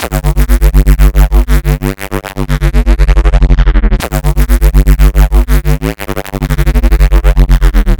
lfooooooo.wav